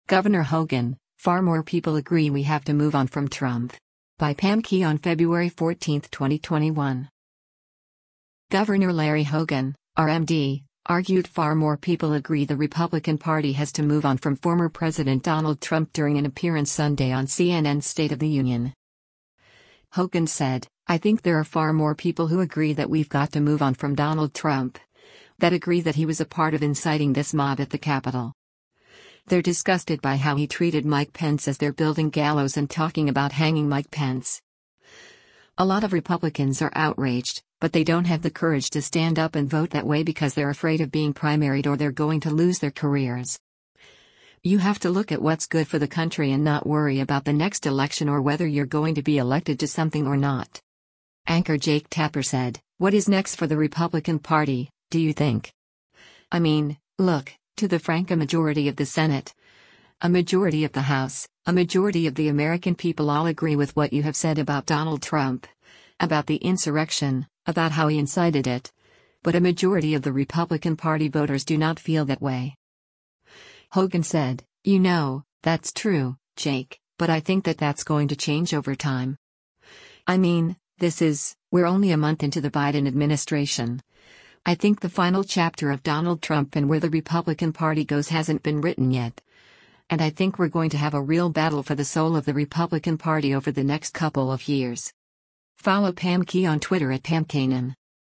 Governor Larry Hogan (R-MD) argued “far more people” agree the Republican Party has to move on from former President Donald Trump during an appearance Sunday on CNN’s “State of the Union.”